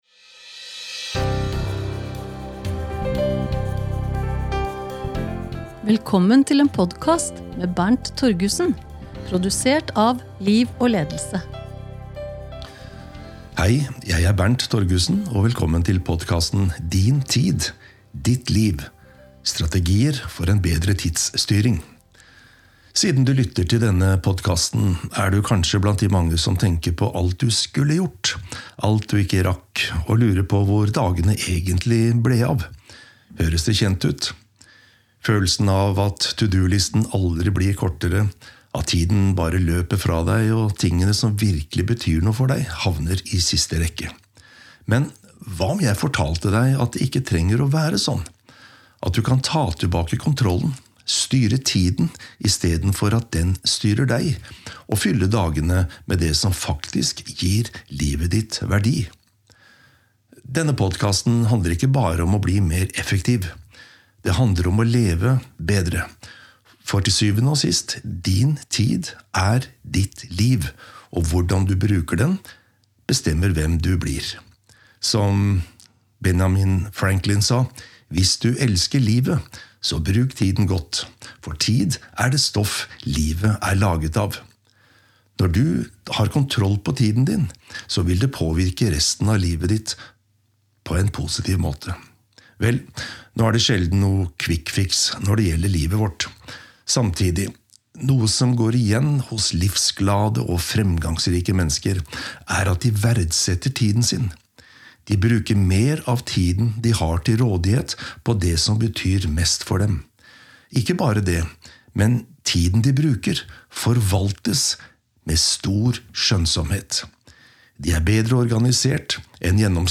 Lydbok: Din tid, Ditt liv